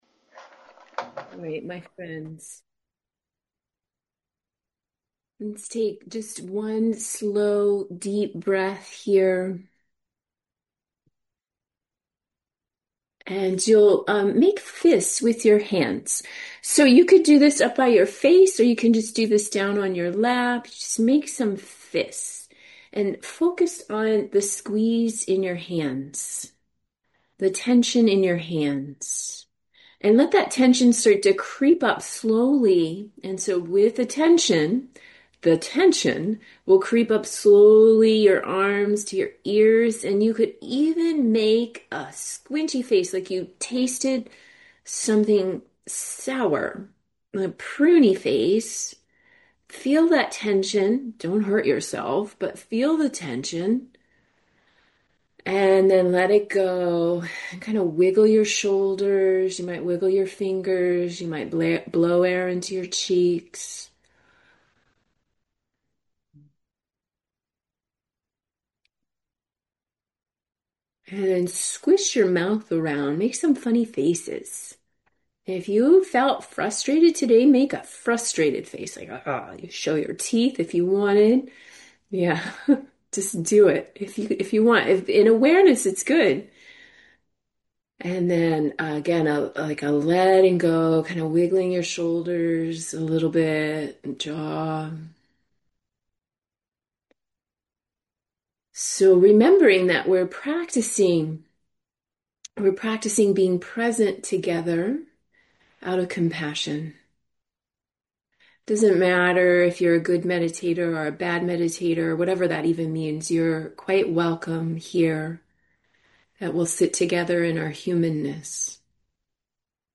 Guided Meditation: Embodied Awareness